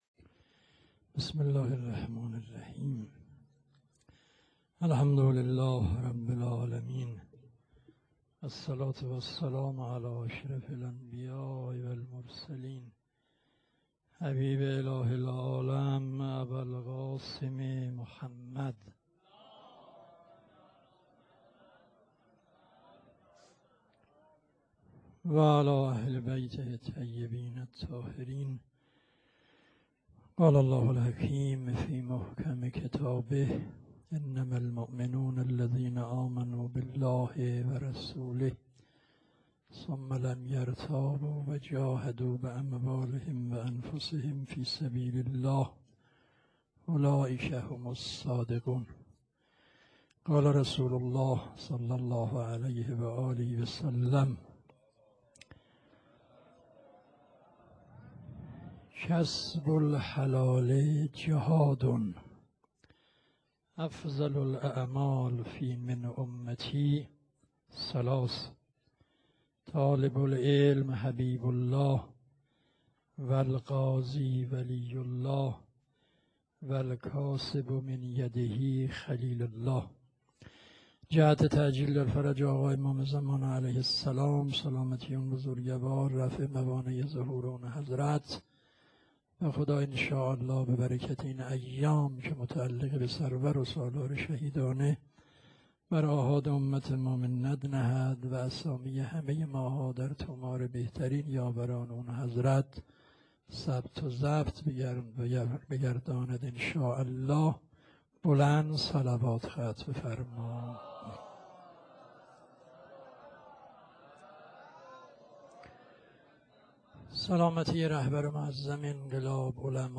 شب ششم _ حاج آقا فلسفی _ سخنرانی.wma
شب-ششم-حاج-آقا-فلسفی-سخنرانی.wma